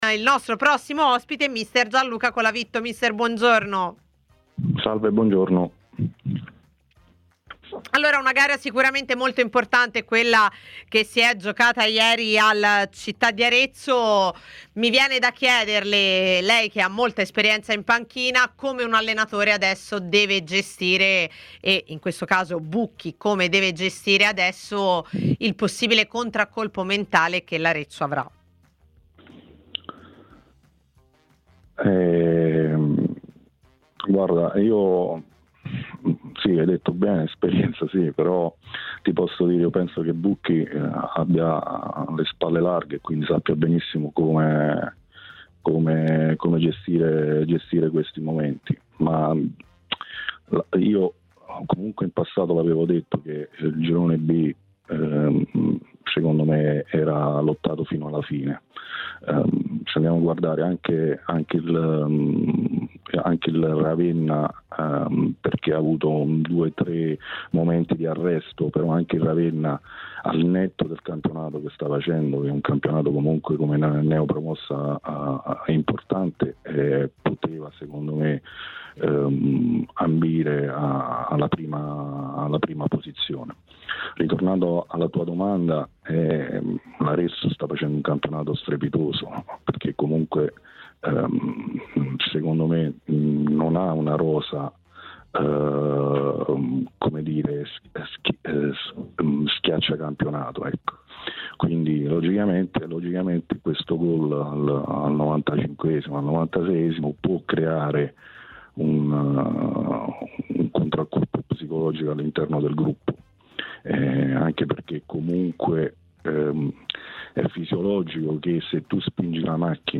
TMW Radio